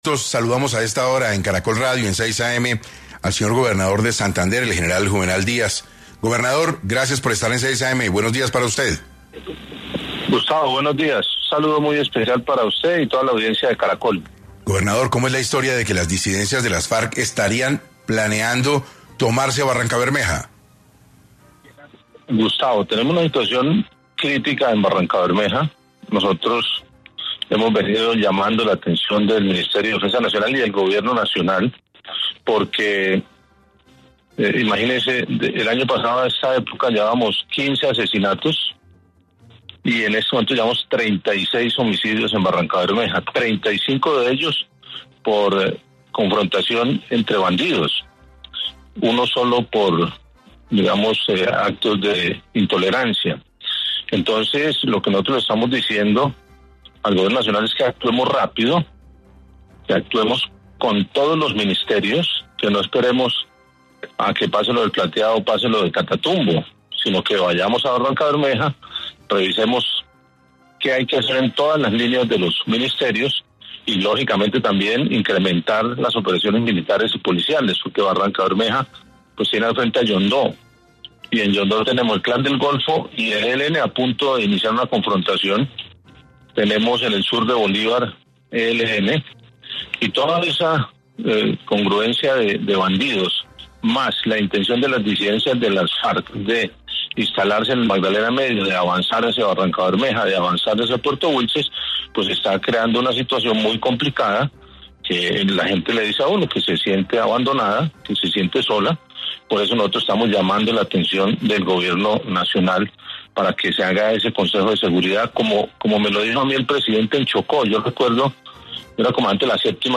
En 6AM estuvo Juvenal Díaz, gobernador de Santander, quien solicitó al Gobierno Nacional actuar rápido para evitar acontecimientos similares a los de El Plateado y el Catatumbo.